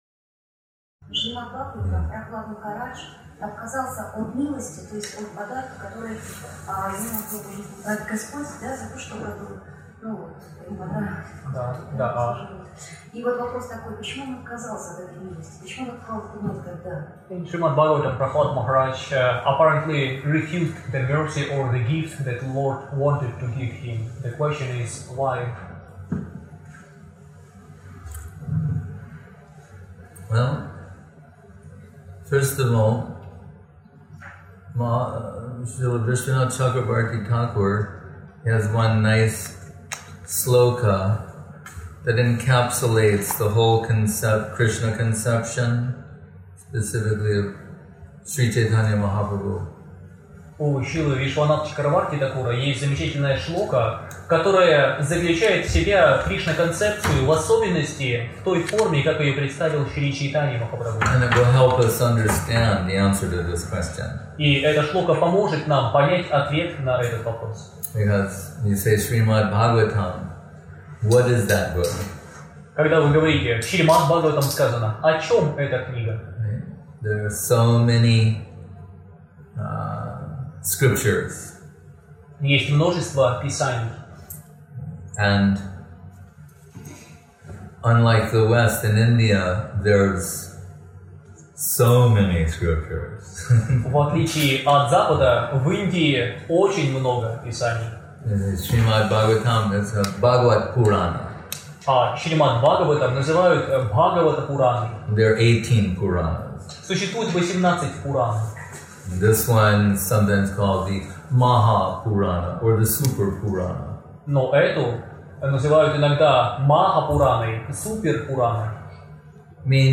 Place: Centre «Sri Chaitanya Saraswati» Moscow